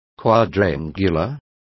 Complete with pronunciation of the translation of quadrangular.